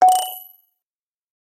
Звуки уведомления чата
Звук собеседника пропал из сети